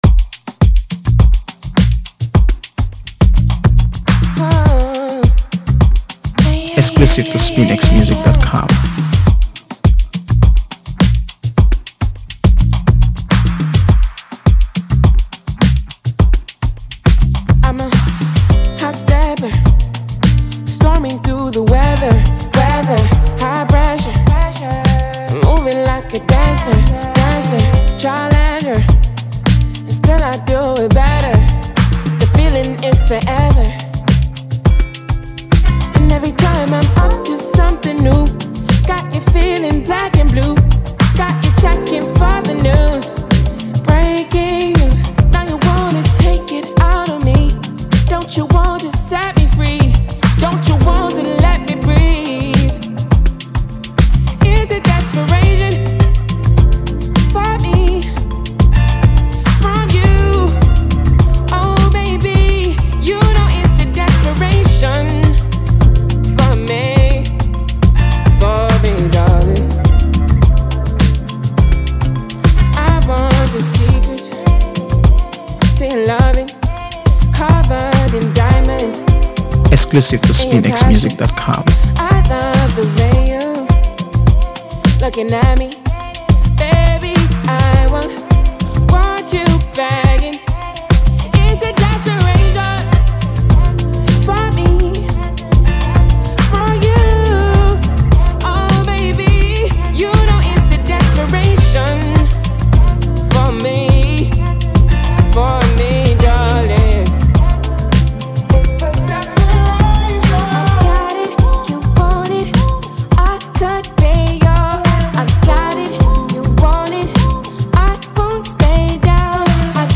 AfroBeats | AfroBeats songs
an emotional and suspenseful new track